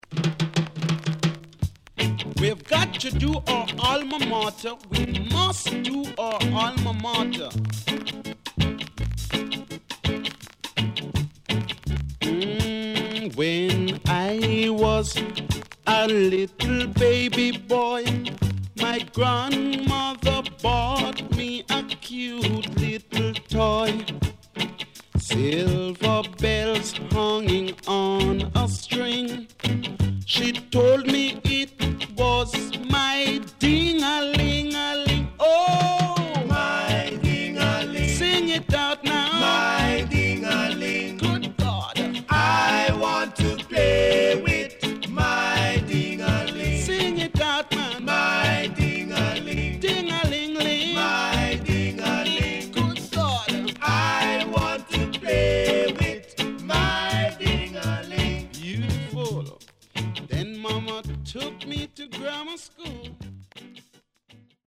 HOME > REGGAE / ROOTS
Side A:所々プチノイズ入ります。